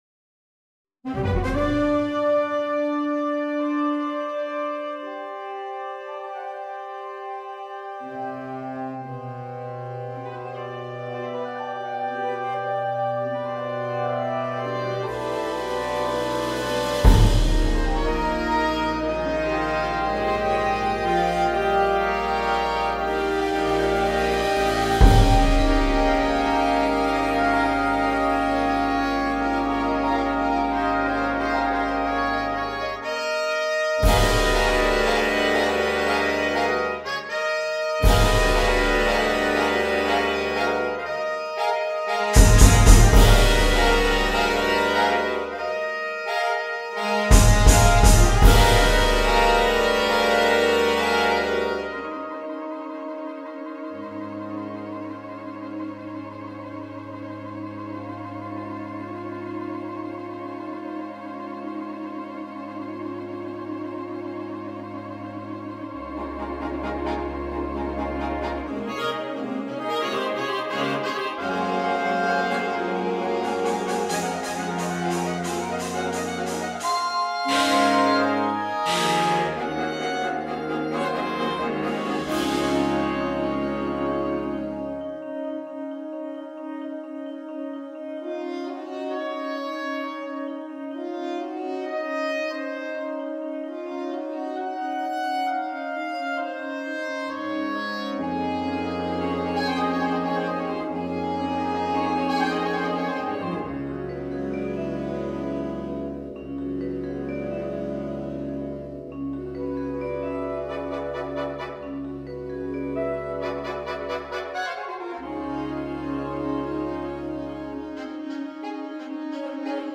Contemporary
SS . AAA . TT . BB . Bs . 2x Perc.
Percussion 2 (Bass drum & vibraphone)
produced using NotePerformer